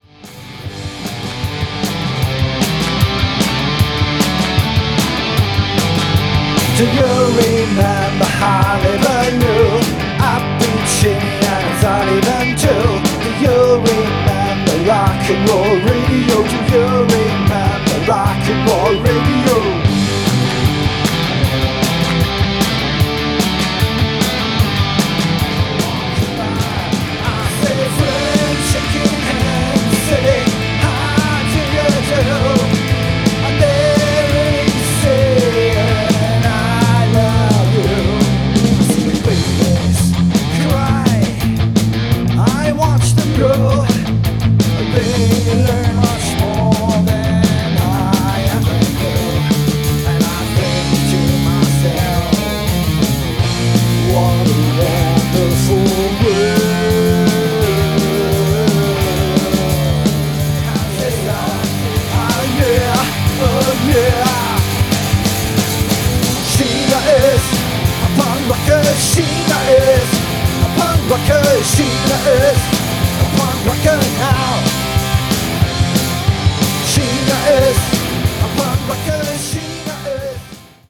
Fünf Jungs, Vollgas, Party.
Pop gemischt mit Punk und Pogo.